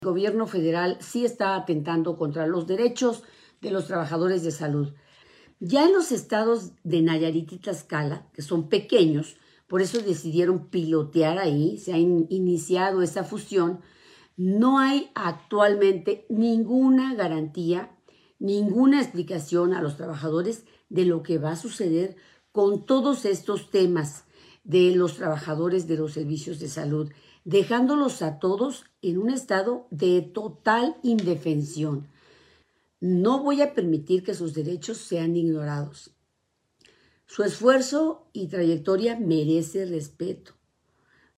A través de su transmisión en vivo por redes sociales, “Despierta con Caro”, la abanderada del PAN, PRI y PRD también advirtió la posibilidad de un auto atentado planeado por el candidato morenista con el objetivo de levantar su campaña.